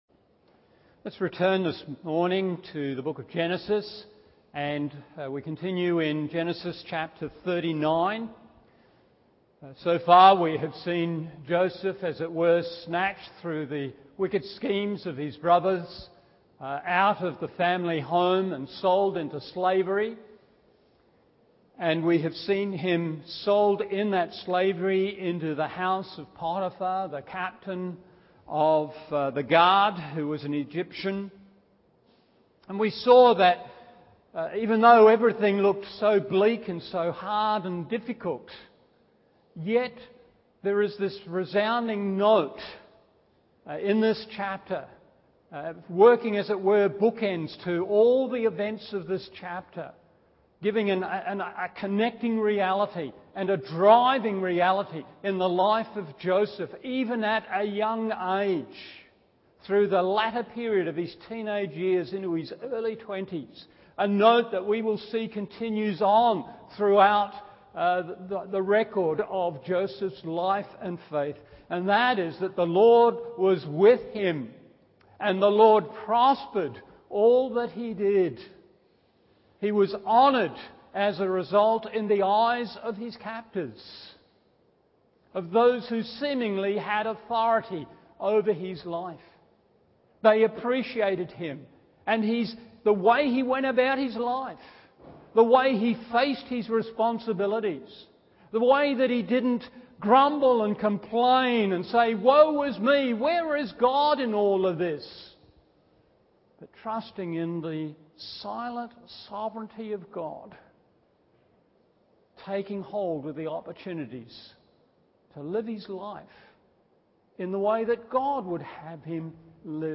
Morning Service Genesis 39:6b-18 1. The Power of Temptation 2. The Principle of Resistance 3. The Provision of Grace…